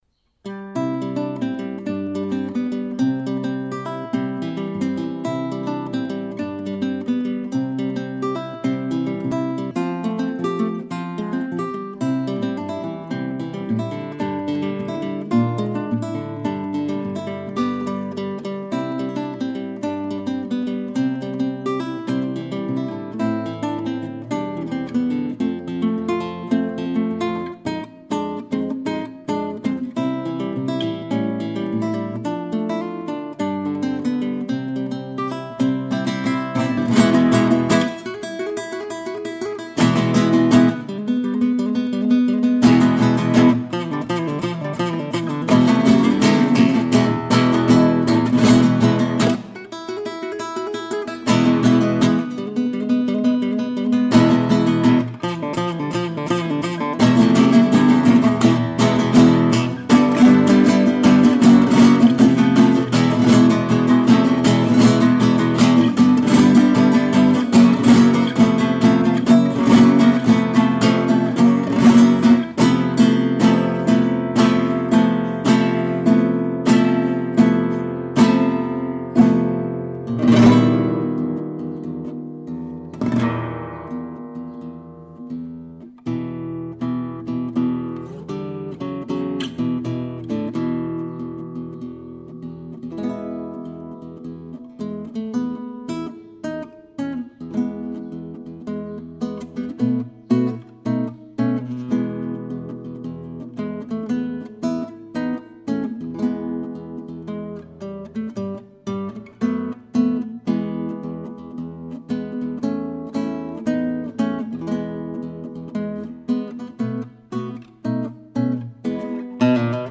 Because it was so huge, she needed the help of all her feathered friends to march back with e worm to the nest, where they had a jig to celebrate, eating worm fritters, worm curry, worm burgers etc. Eventually all they had the strength to do was to sing a lullaby to the baby birds. And all that is in this piece of music.